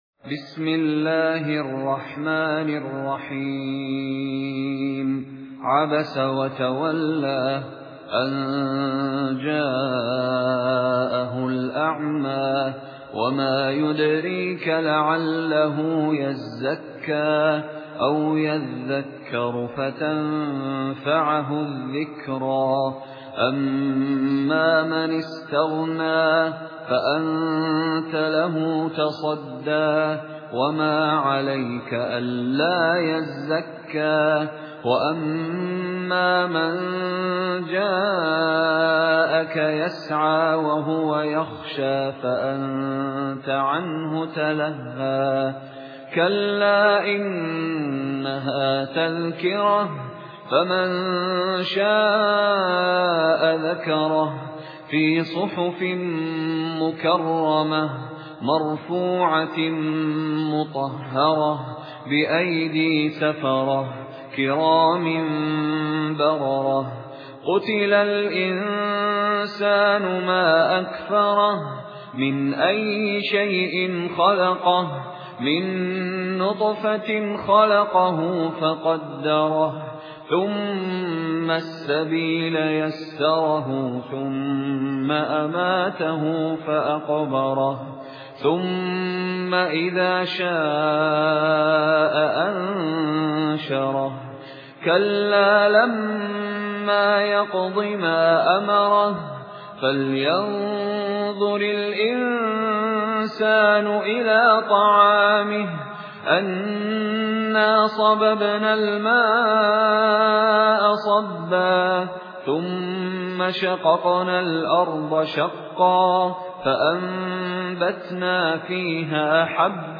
مشاري راشد العفاسي ( قصر المنفصل من طريق الطيبة )